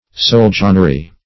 Search Result for " soldanrie" : The Collaborative International Dictionary of English v.0.48: Soldanrie \Sol"dan*rie\, n. The country ruled by a soldan, or sultan; a sultanate[2].